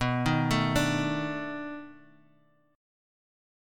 B7#9 chord